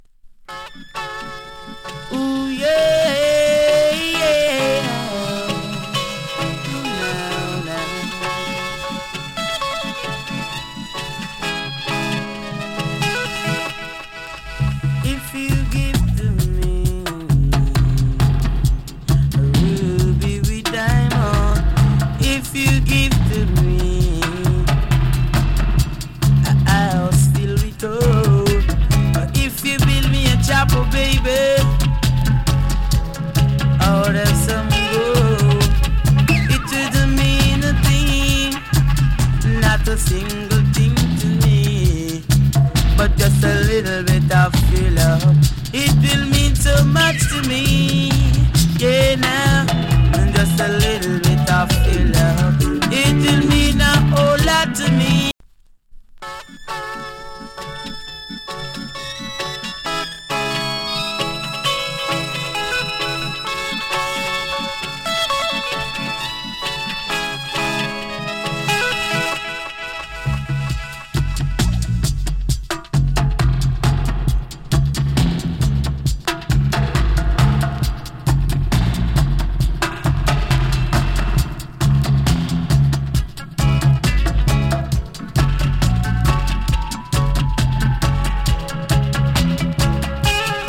KILLER ROOTS VOCAL.